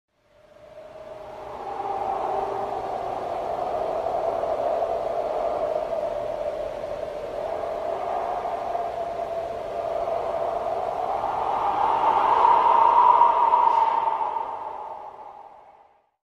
Звуки дементора
Атмосферный звук при приближении дементоров